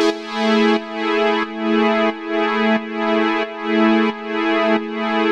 Index of /musicradar/sidechained-samples/90bpm
GnS_Pad-MiscA1:4_90-A.wav